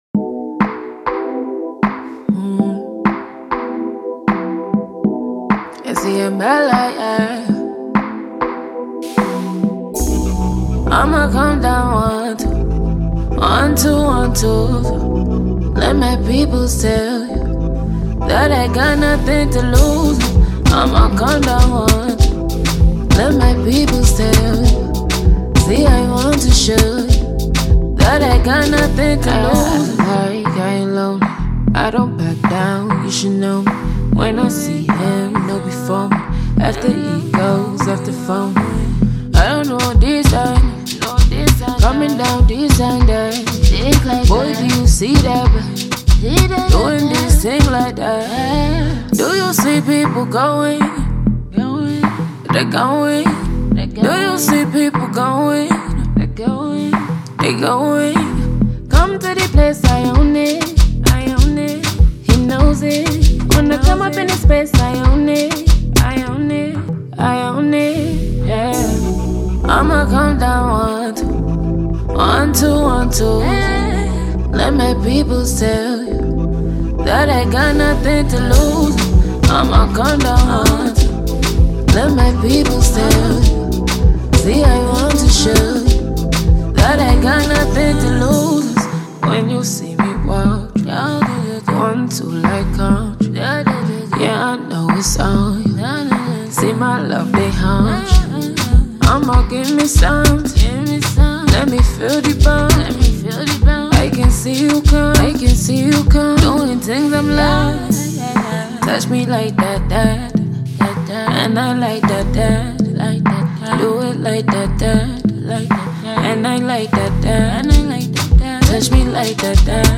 British Nigerian sultry singer
Backed by a sultry yet bouncy African influenced beat
gives you something to dance to